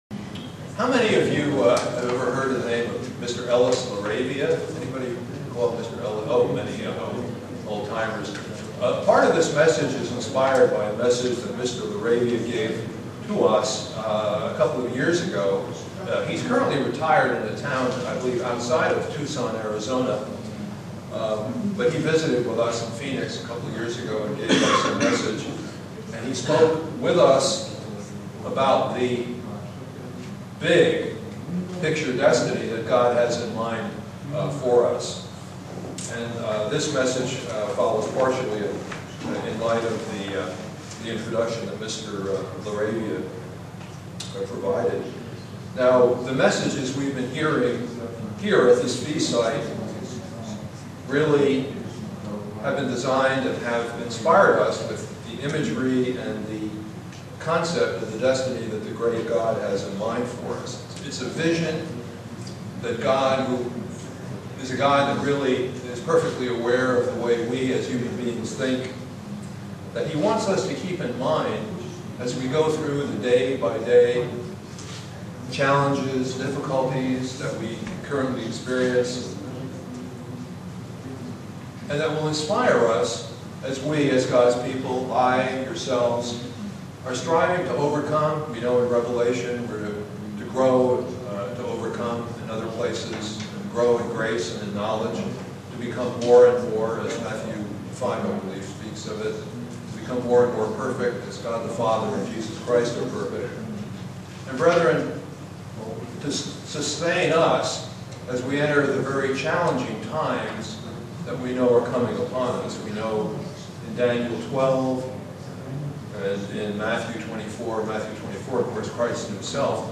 Print A vision to look to as we go through our trials and trials to come. sermon Studying the bible?
Given in Buffalo, NY Elmira, NY